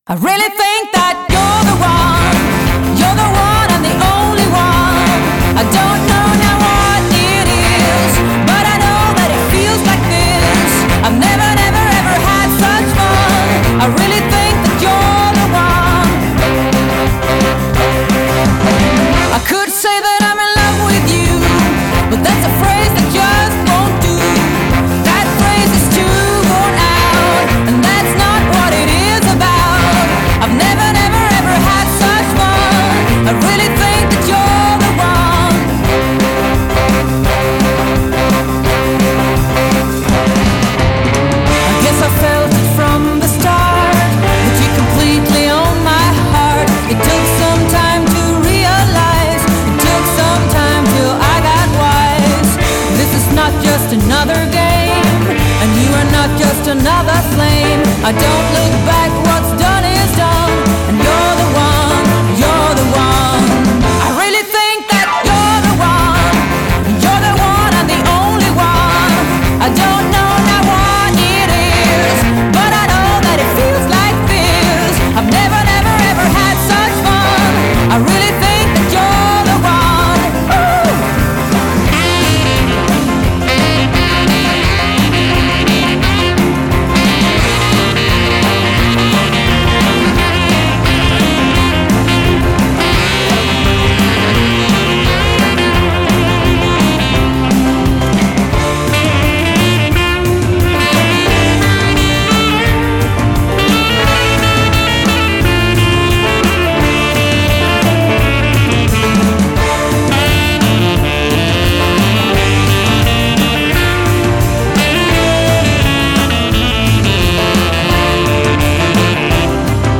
Svängig 50-tals rock, rockabilly, blues och rock'n'oll.